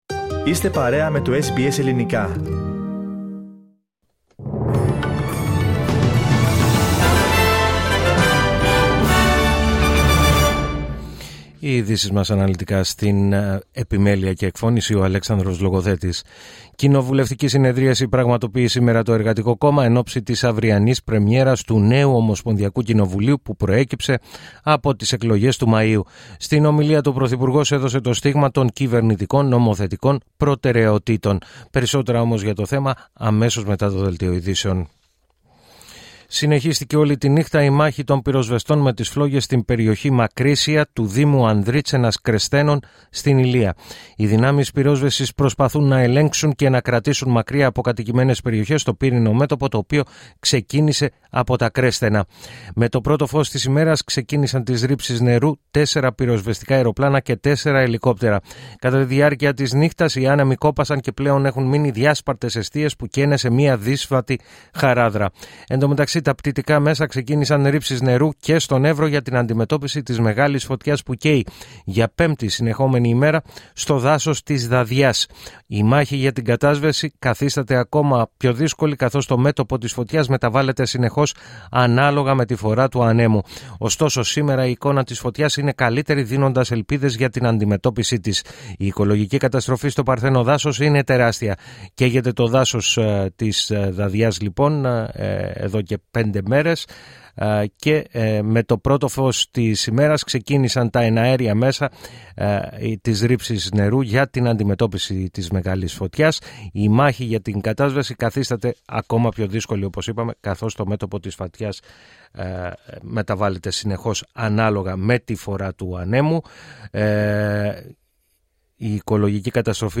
Το αναλυτικό δελτίο του Ελληνικού Προγράμματος της ραδιοφωνίας SBS, στις 16:00.